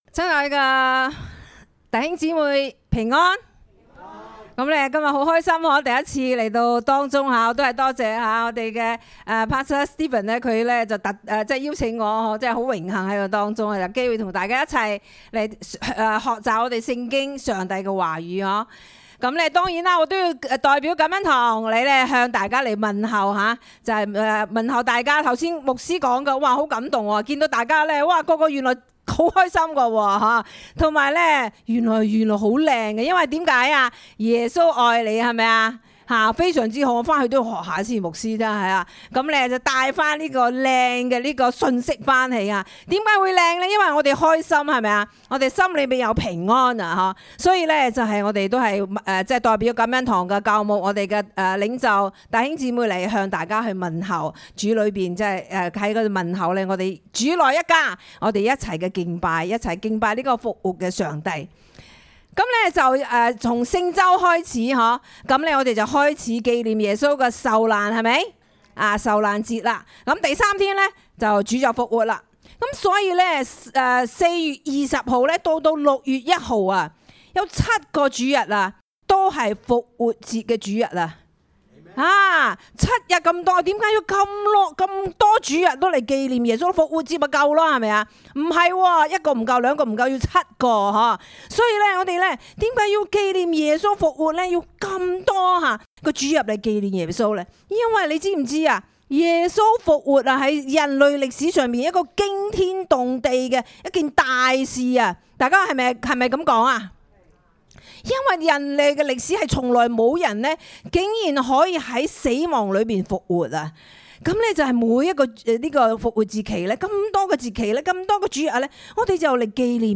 粤语堂讲道音频